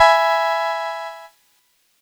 Cheese Chord 10-E3.wav